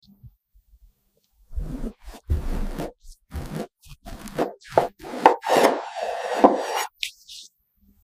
Cutting cake 🎂 best asmr sound effects free download